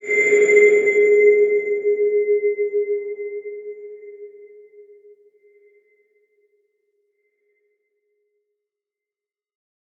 X_BasicBells-G#2-pp.wav